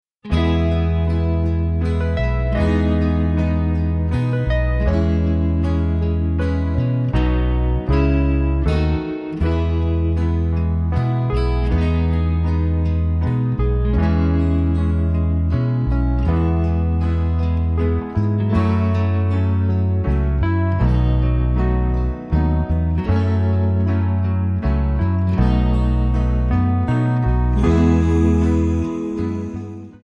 Backing track files: All (9793)